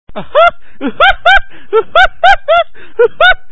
gay_laugh